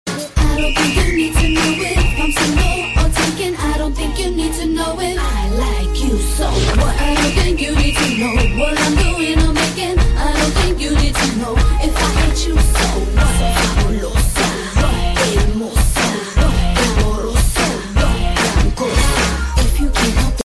Blues Ringtones